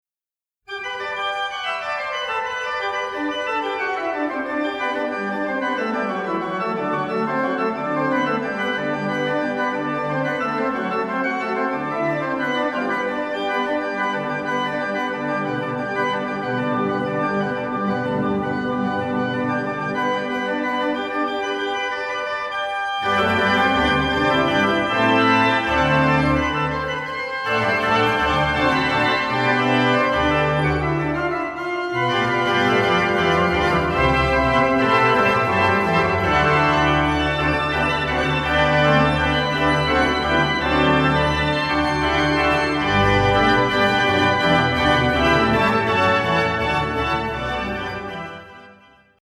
helkkyvästä
plenosta